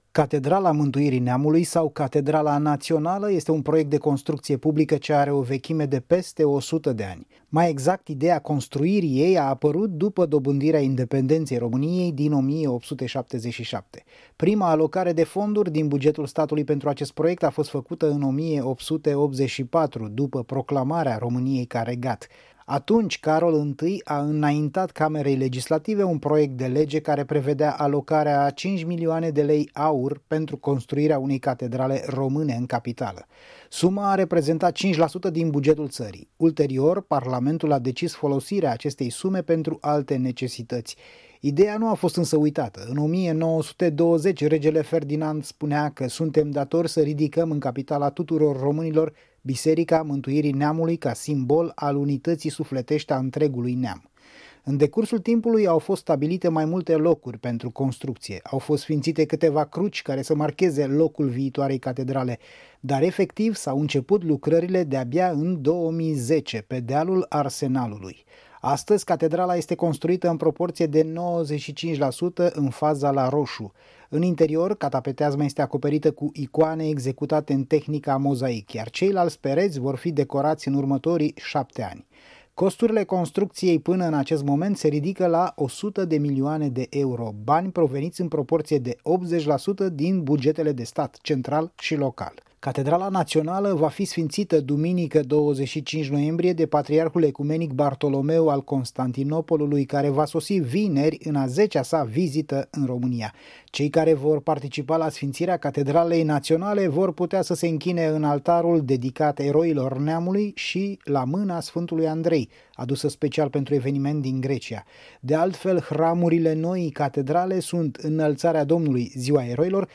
stiri-18-nov-catedrala.mp3